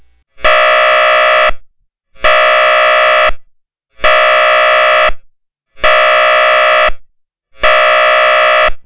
Provides up to 85 dB at 5 feet.
102/108 stutter_beep Wav File - 195.4K
stutter_beep.wav